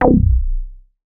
MoogResFilt 002.WAV